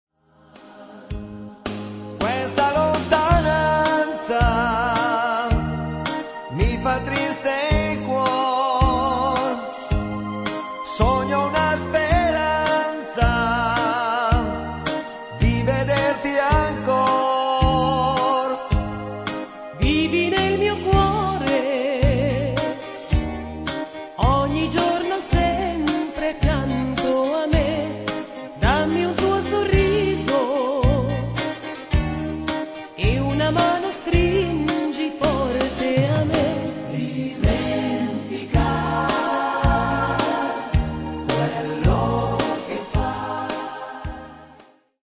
sirtaki